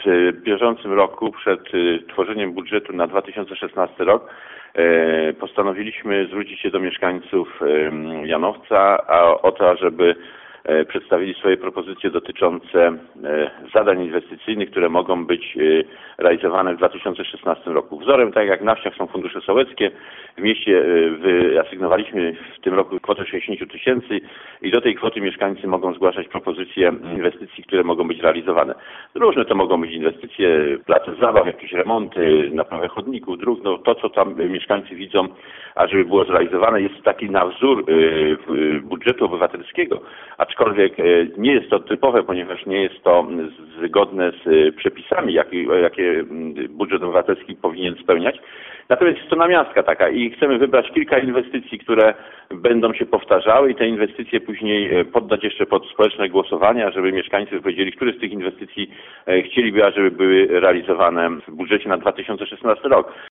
Co prawda nie jest to budżet obywatelski, jednak w związku z trwającymi pracami nad projektem budżetu gminy na 2016 rok mieszkańcy Janowca Wielkopolskiego będą mogli składać propozycje przedsięwzięć do wykonania na terenie miasta, mówi burmistrz Janowca Maciej Sobczak.